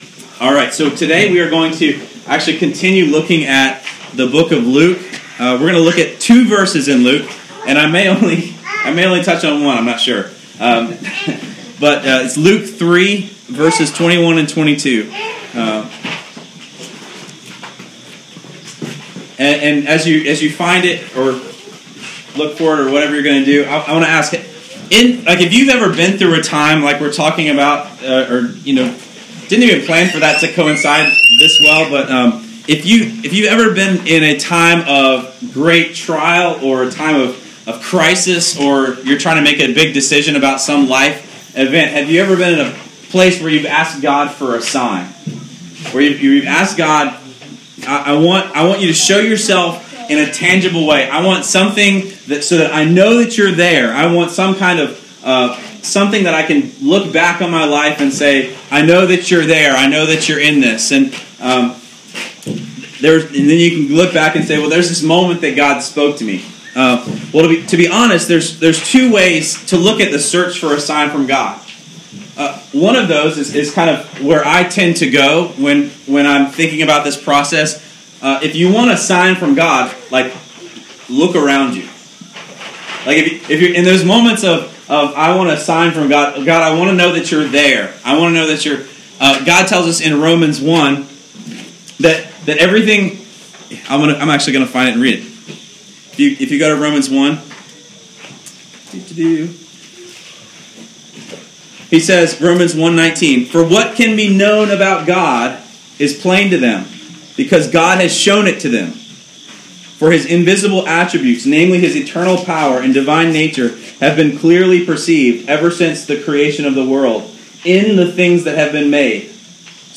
praise-in-the-park.m4a